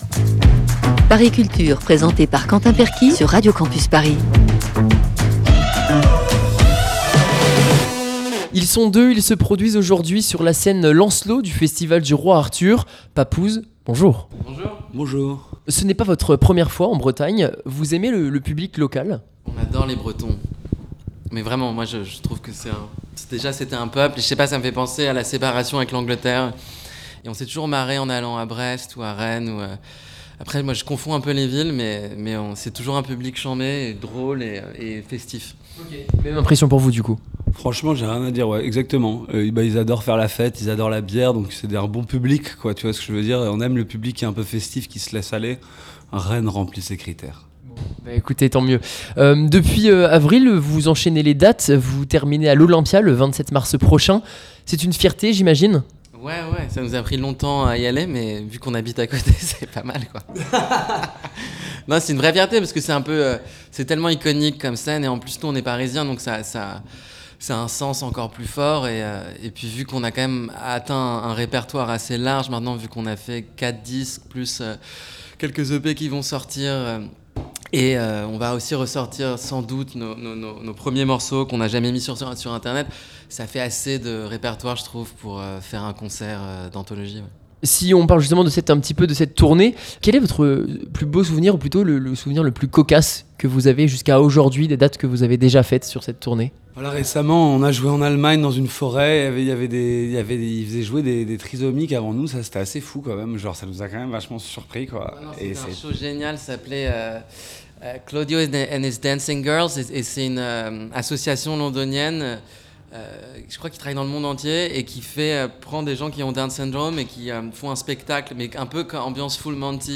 Le duo Papooz nous accorde une interview avant leur concert lors du festival du Roi Arthur cet été près de Rennes.